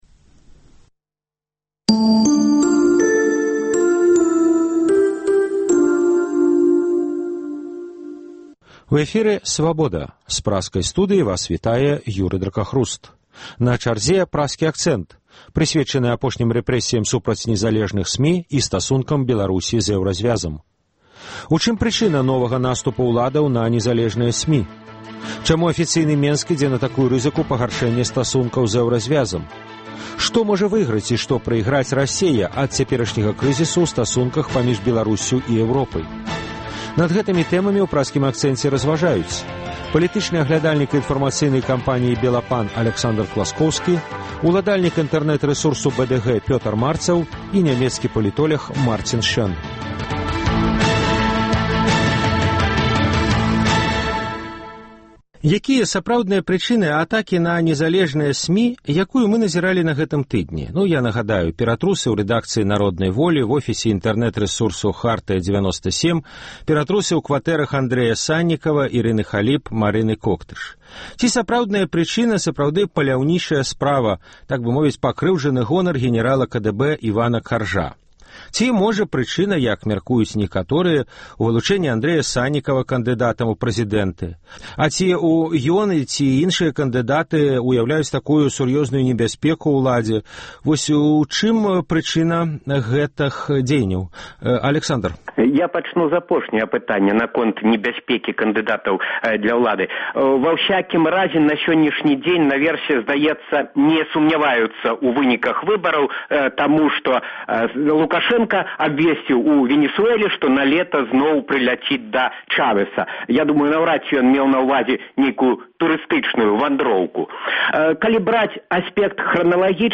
У чым прычына новага наступу ўладаў на незалежныя СМІ? Чаму афіцыйны Менск ідзе на такую рызыку пагаршэньня стасункаў з Эўразьвязам? Што можа выйграць і што прайграць Расея ад цяперашняга крызісу ў стасунках паміж Беларусьсю і Эўропай? Над гэтымі тэмамі ў “Праскім акцэнце” разважаюць: